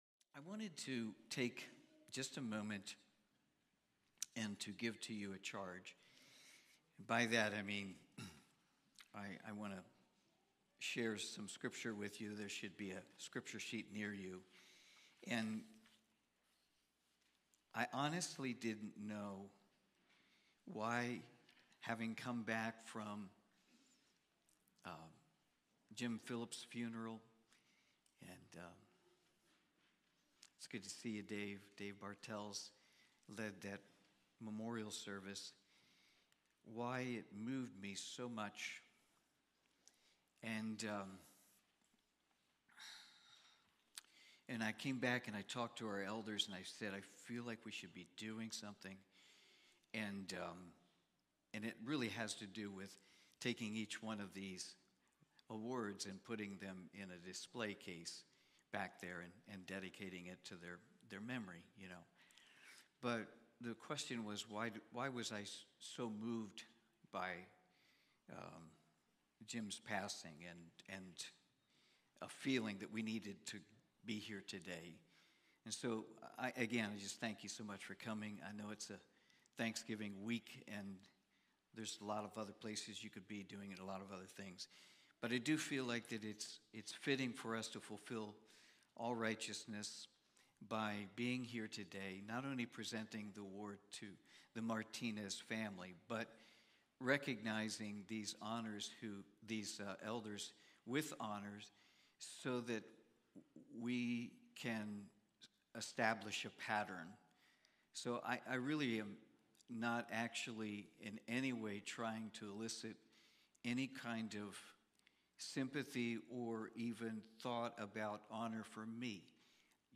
1 Corinthians Watch Listen Save Cornerstone Fellowship Sunday morning service, livestreamed from Wormleysburg, PA.